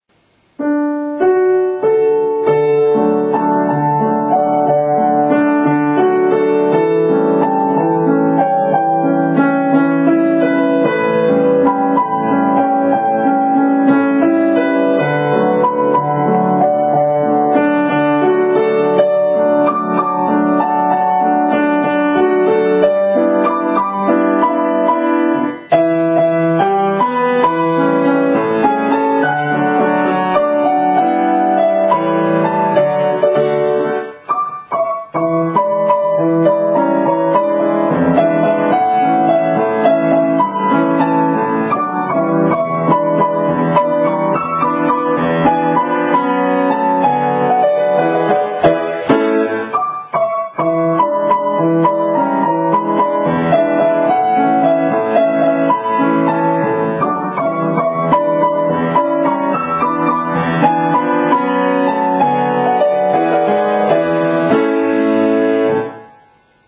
難易度は、エリーゼのためによりも易しいです。